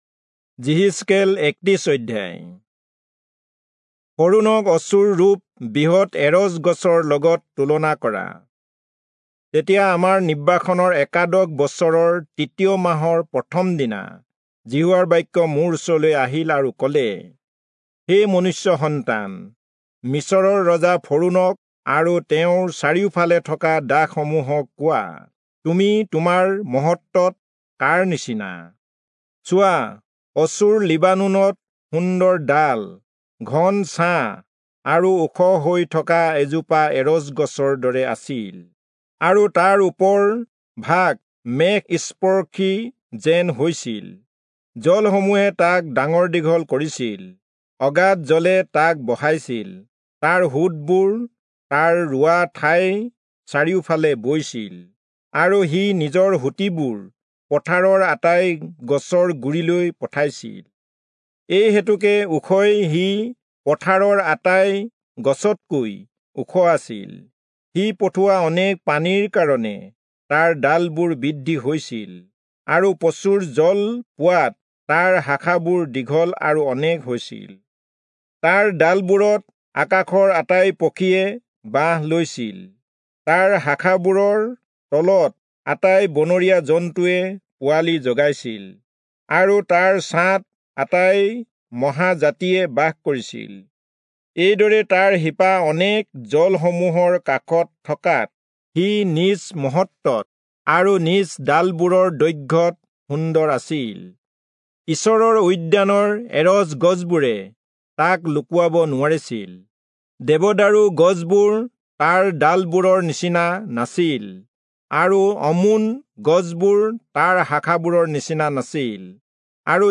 Assamese Audio Bible - Ezekiel 17 in Ervkn bible version